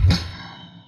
Aunt Wang Syrup Theme Song Snare.wav